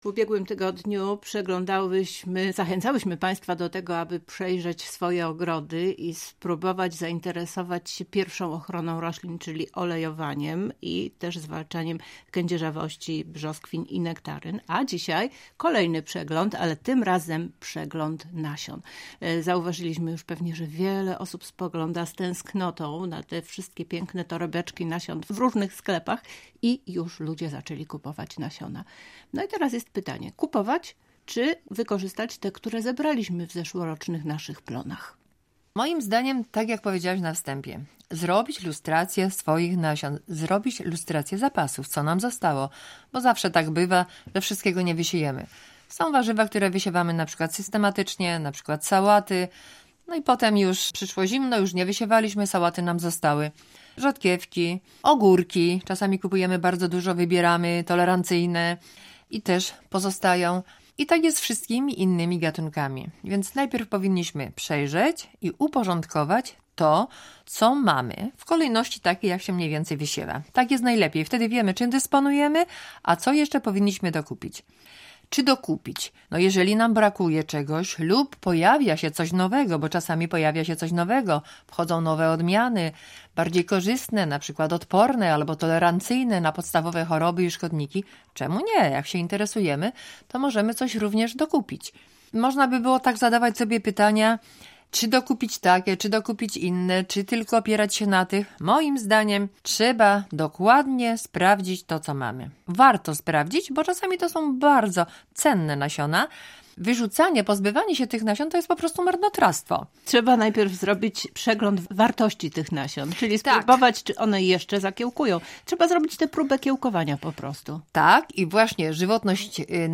Więcej w rozmowie